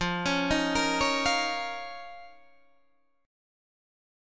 F7sus4#5 Chord